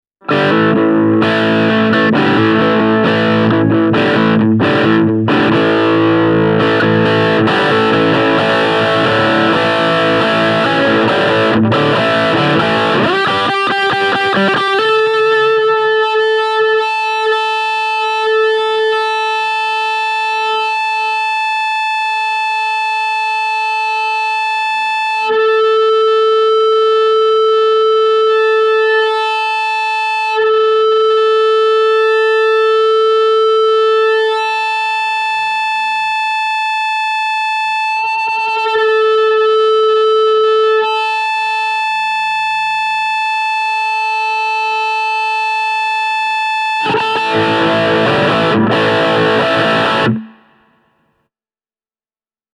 Puhtailla vahvistinasetuksilla pedaalin soundi tuo mieleen E-Bow-tyylisiä äänimaailmoja, mutta itse käyttäisin FB-2:ta enemmän särökanavalla, koska soundi on silloin laulavampi ja koko touhua on särön tuoman kompression ansiosta selvästi helpompi hallita.
Ääniesimerkki on äänitetty hyvin maltillisella volyymillä Roland Micro Cuben (Brit Combo -vahvistinmalli) kautta:
boss-fb-2-feedbacker_booster.mp3